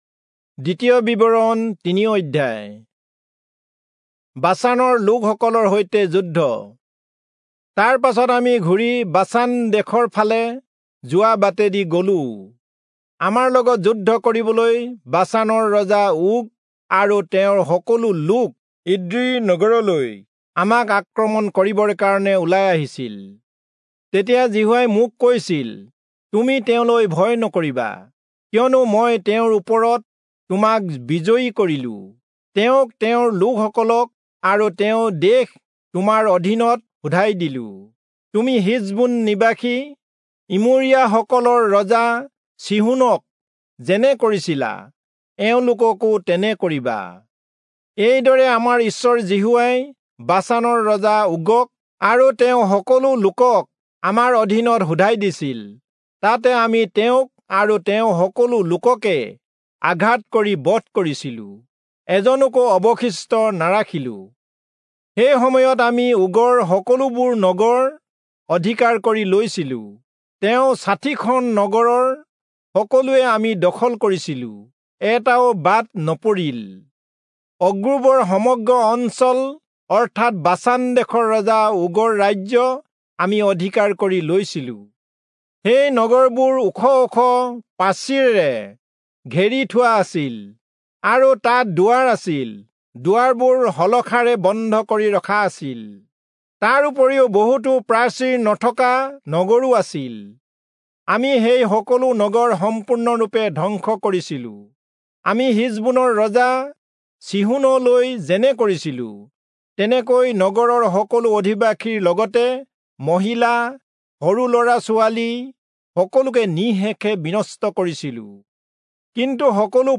Assamese Audio Bible - Deuteronomy 12 in Web bible version